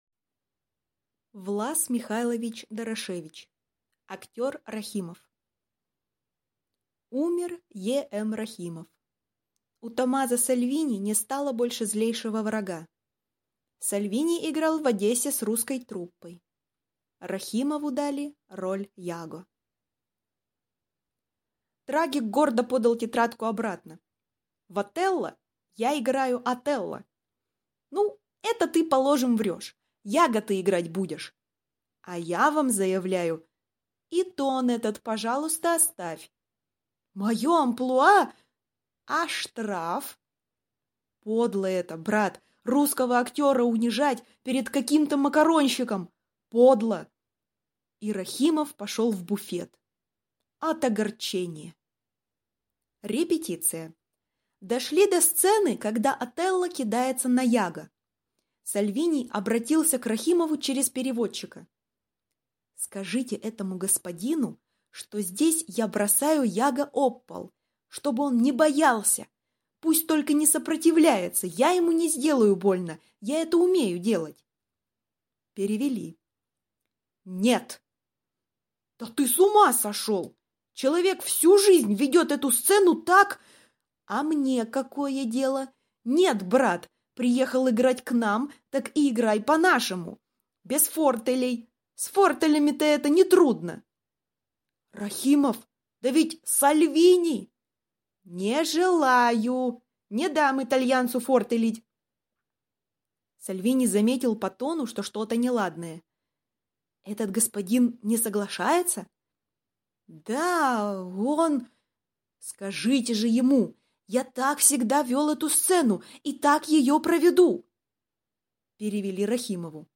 Аудиокнига Актер Рахимов | Библиотека аудиокниг